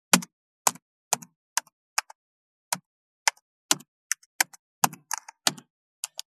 33.タイピング【無料効果音】
ASMRタイピング効果音
ASMR